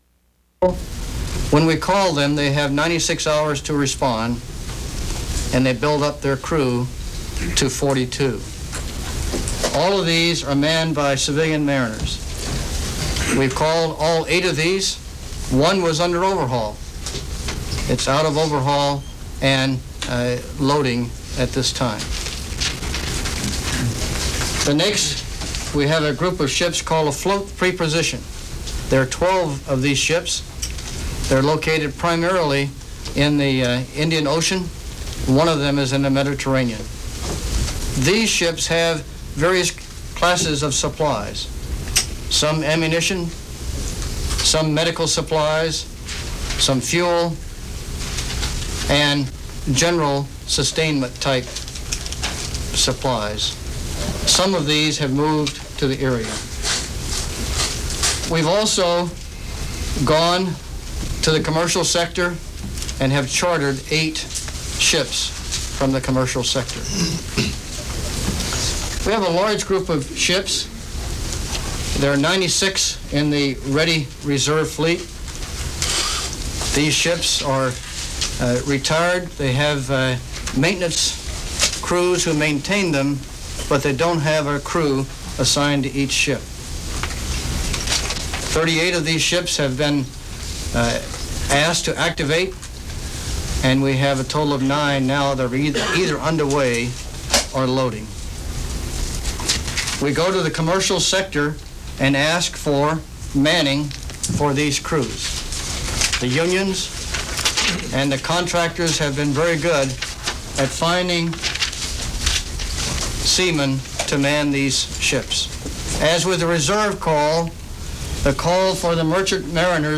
General H.T. Johnson, Army Transportation Command Chief, in a Pentagon briefing on the transporting of men and material to the Middle East during the Iraq/Kuwait invasion. He tells anecdotes of cooperation above and beyond the call of duty
Recorded from CNN, August 21, 1990.